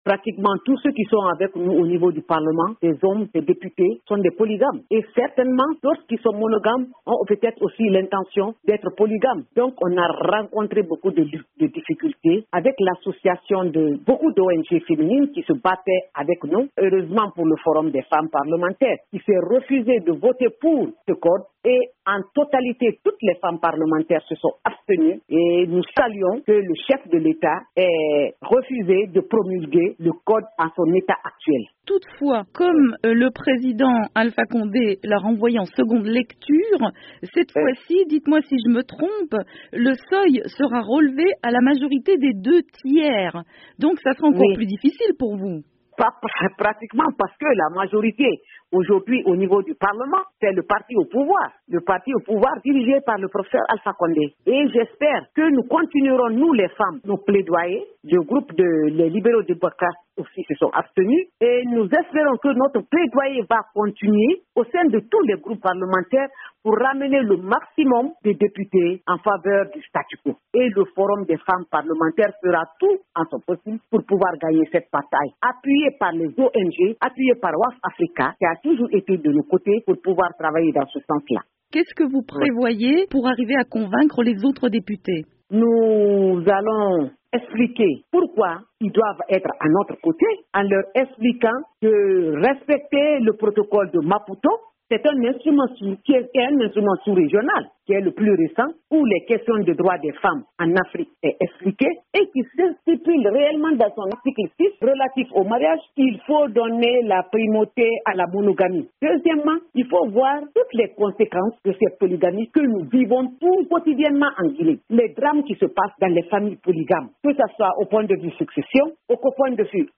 Le président guinéen Alpha Condé a refusé de promulguer le Code civil, voté au parlement le 29 décembre, qui rétablit la polygamie. Il a renvoyé le texte pour une seconde lecture. Les femmes députées s'étaient abstenues lors du vote, comme Aissata Daffé, députée UFR, jointe par VOA Afrique.